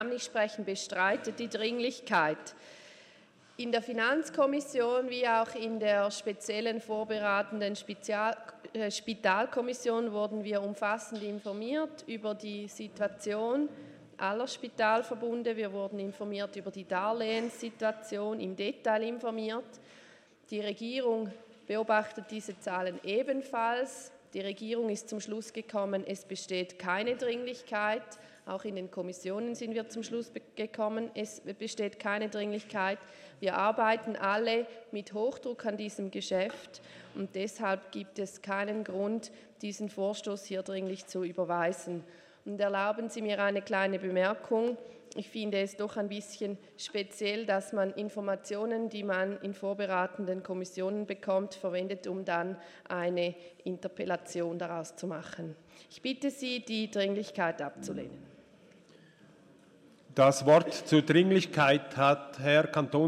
24.4.2019Wortmeldung
Session des Kantonsrates vom 23. und 24. April 2019